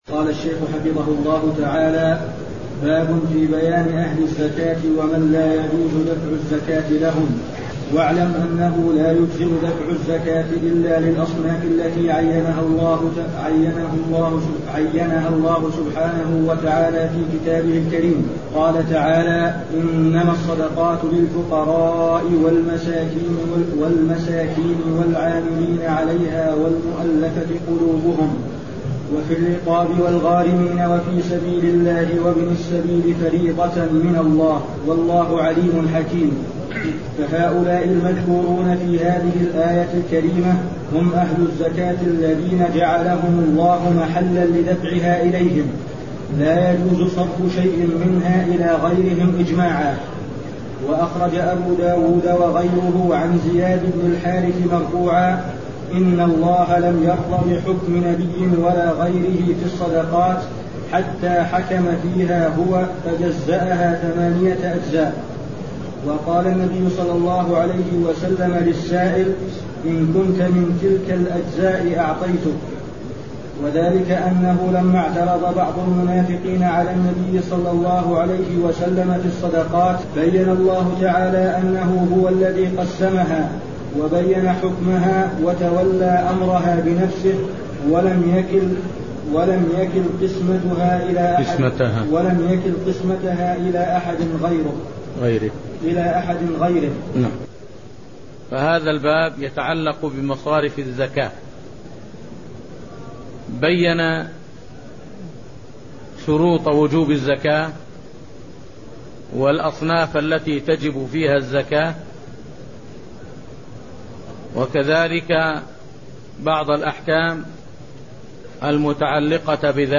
المكان: المسجد النبوي الشيخ: فضيلة الشيخ د. صالح بن سعد السحيمي فضيلة الشيخ د. صالح بن سعد السحيمي باب-زكاة الفطر (0007)